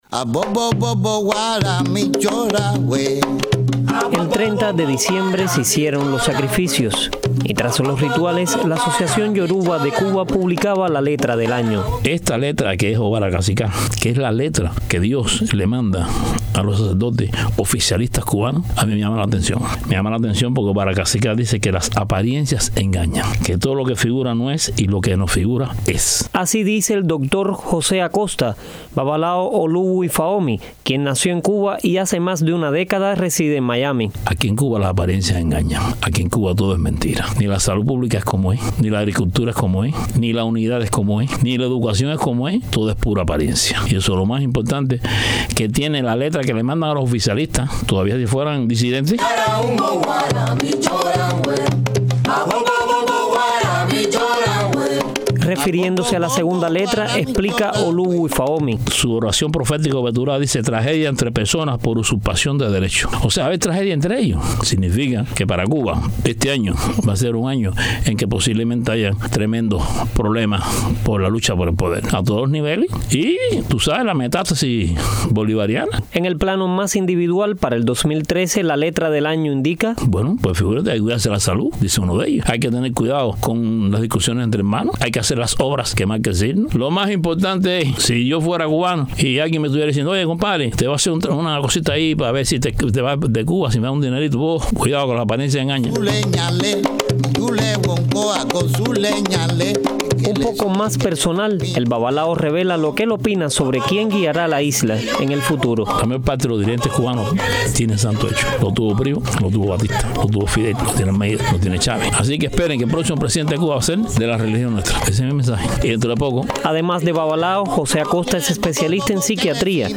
Escuchemos lo que conversó en nuestros estudios